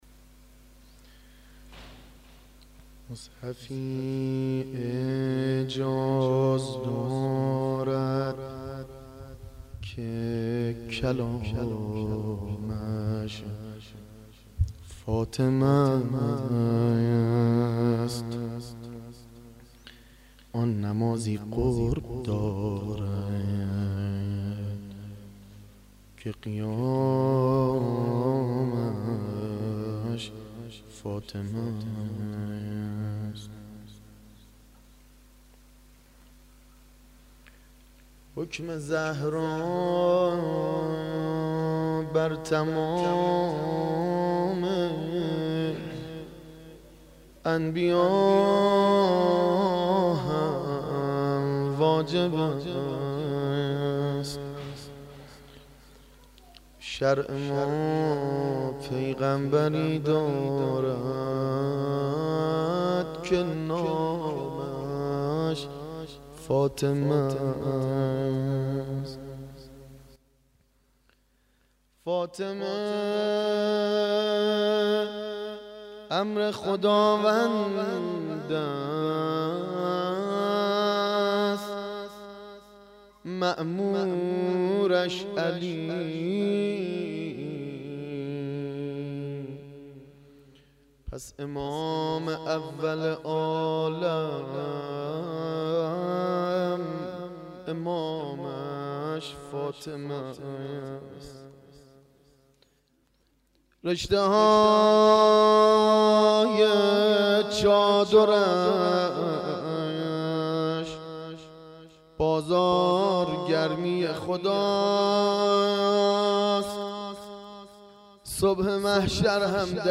مداح